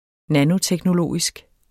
Udtale [ ˈnanotεgnoˈloˀisg ]